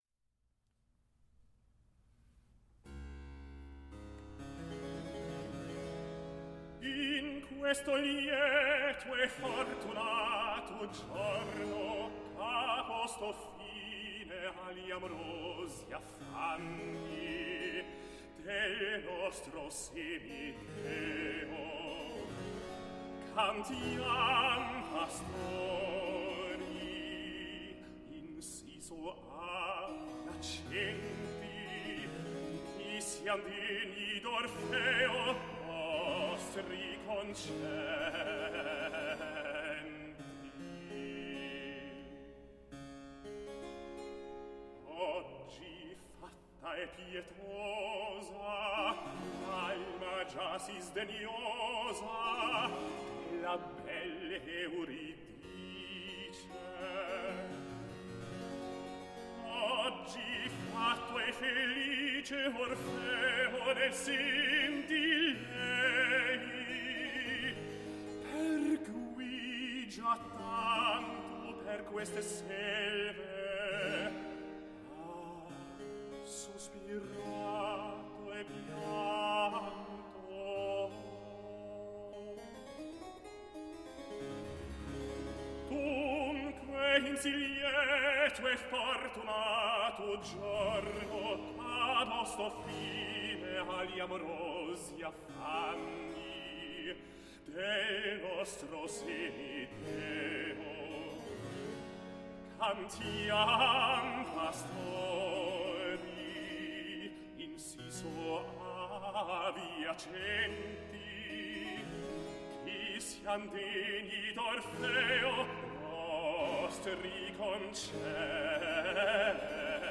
Tenor
Cembalo